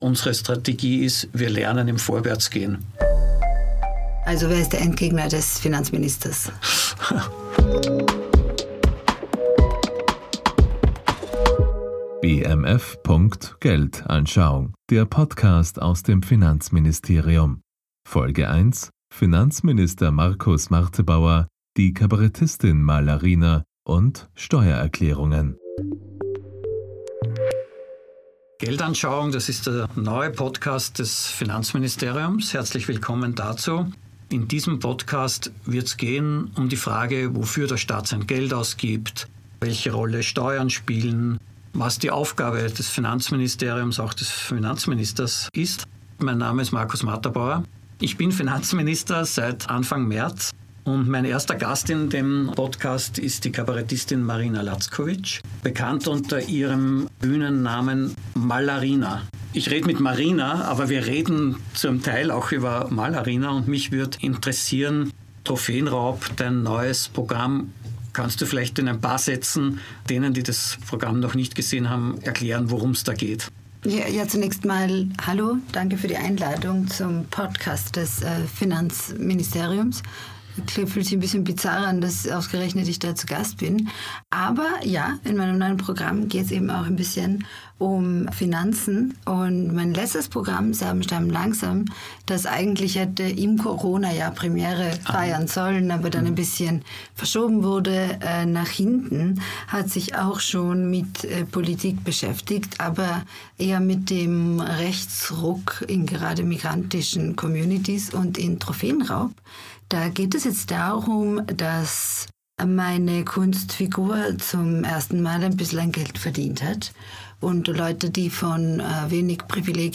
Marina Lacković – besser bekannt unter ihrem Künstlernamen Malarina – und Österreichs Finanzminister Markus Marterbauer sprechen über Steuern, wofür der Staat die Steuereinnahmen verwendet und Alltagsökonomie.